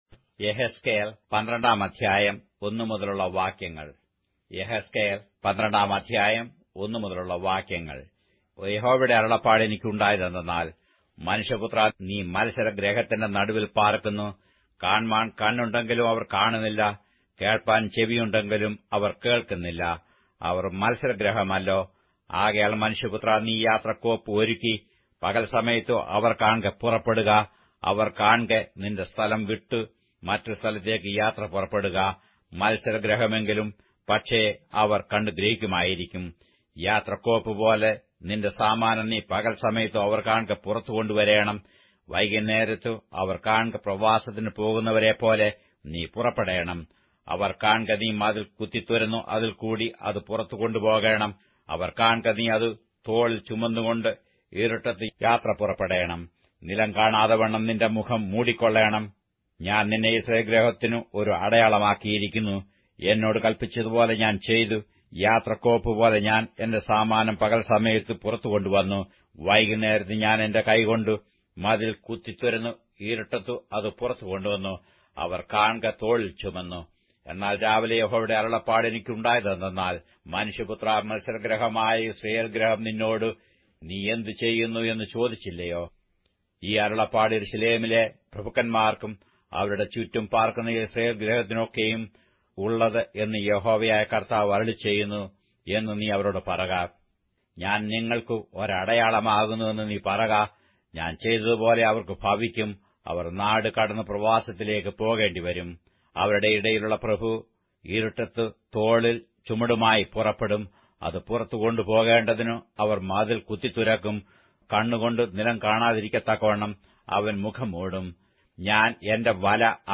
Malayalam Audio Bible - Ezekiel 8 in Irvgu bible version